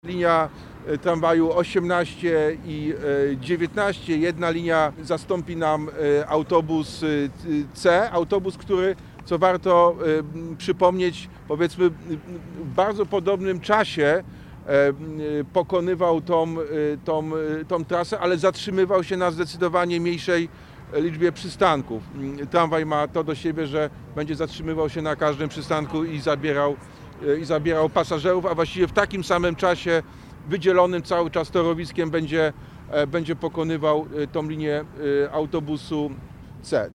Mówi Jacek Sutryk.